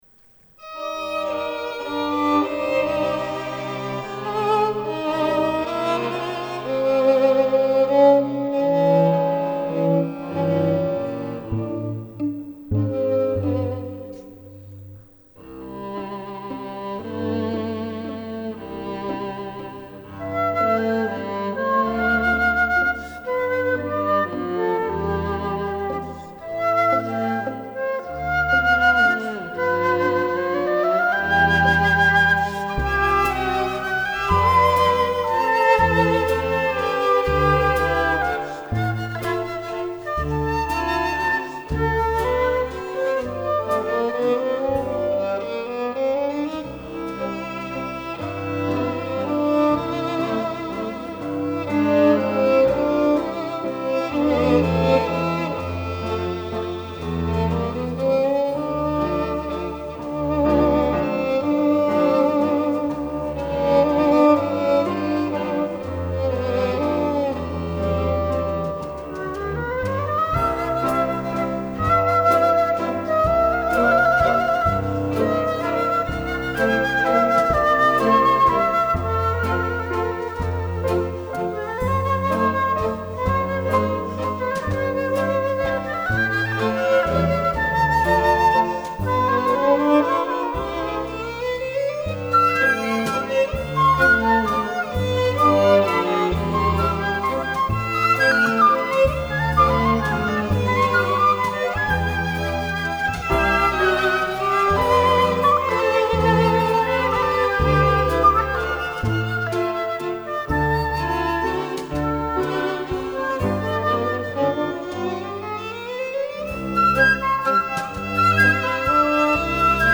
pianoforte
flauto
contrabbasso
batteria